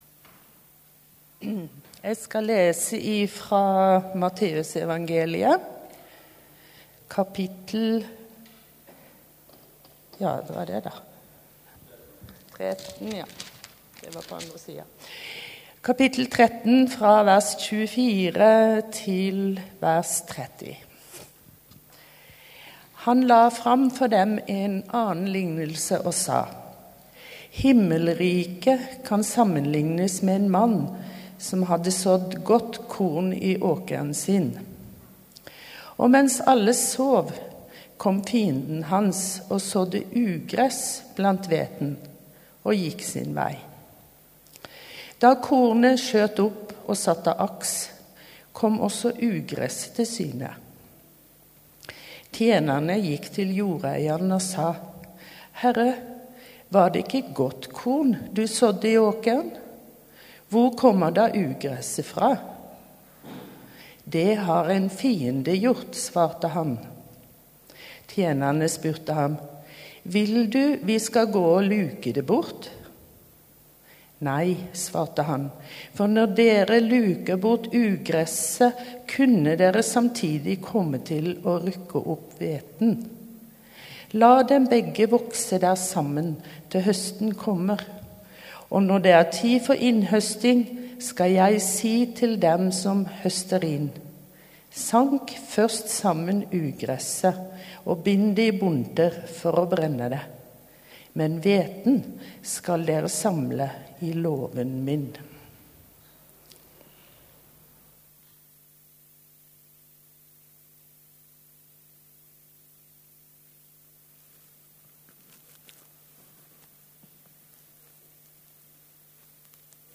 Gudstjeneste 13. februar 2022,-ugresset og hveten | Storsalen